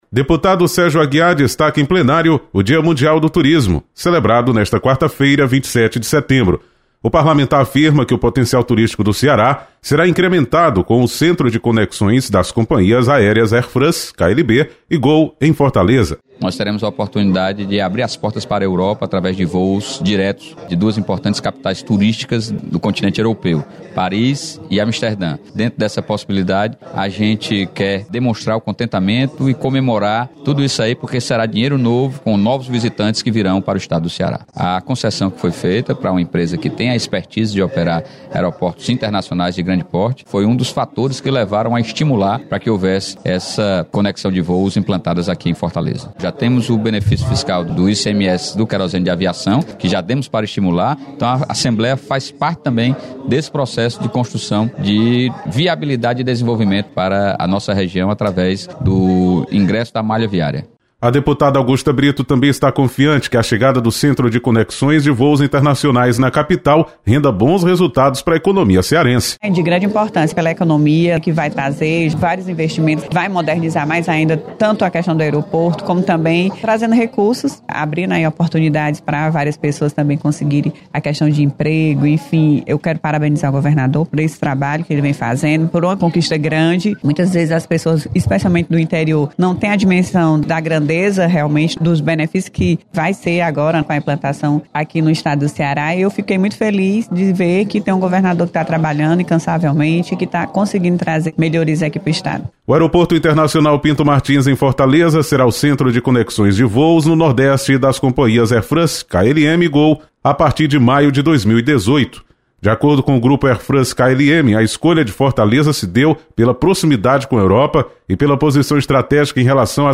Deputado Sérgio Aguiar comemora Dia  Mundial do Turismo e chegada do hub da Air France em Fortaleza. Repórter